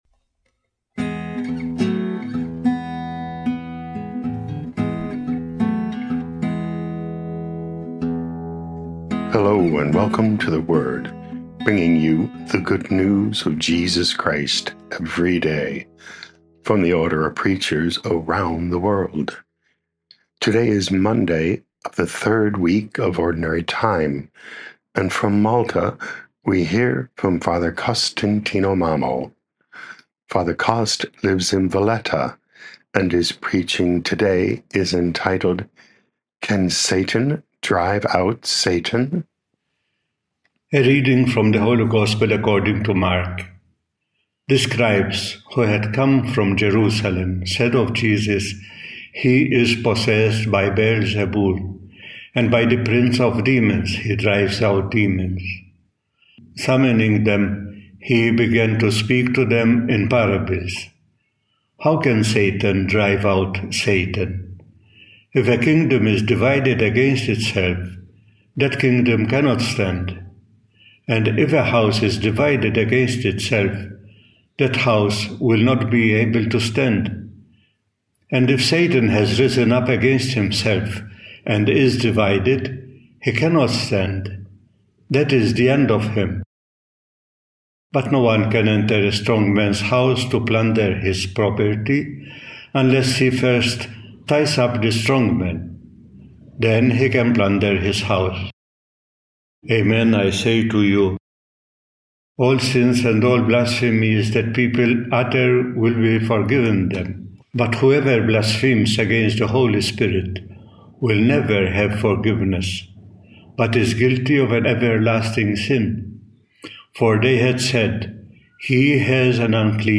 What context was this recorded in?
Podcast: Play in new window | Download For 27 January 2025, Monday of week 3 in Ordinary Time, based on Mark 3:22-30, sent in from Valletta, Malta.